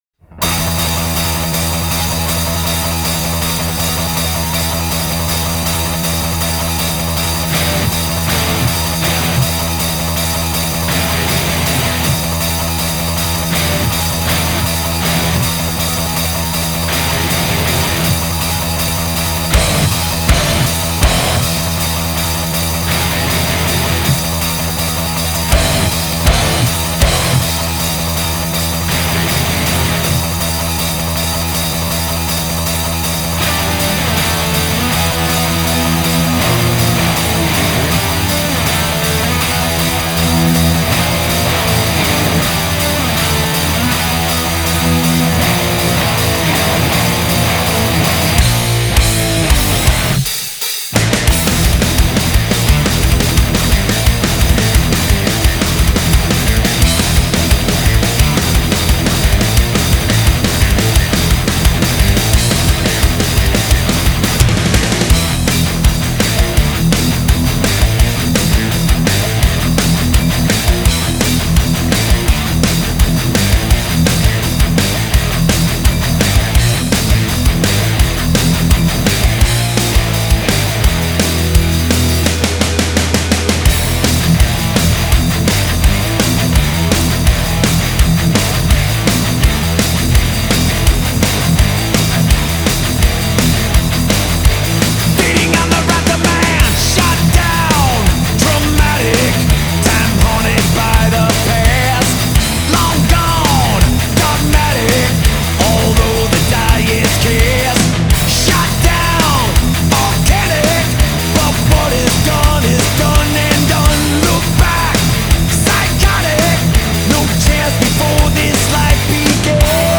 سبک موسیقی متال